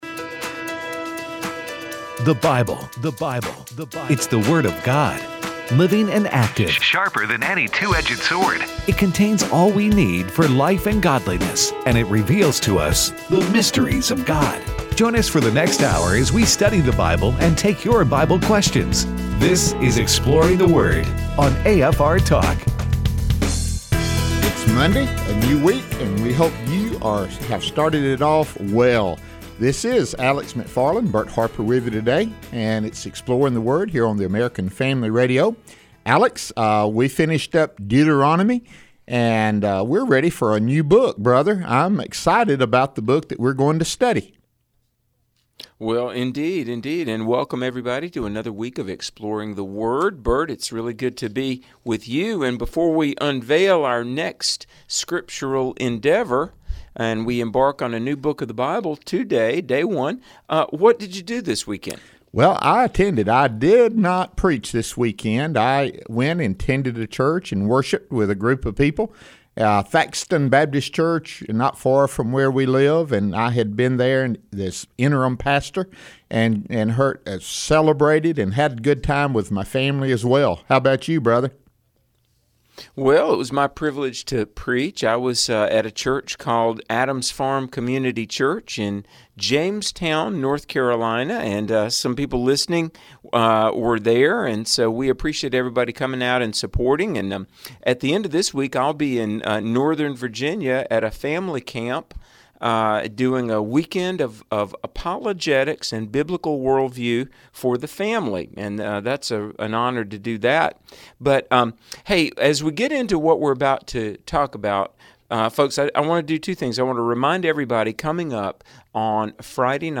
Also, they take your phone calls in the last segment of the show.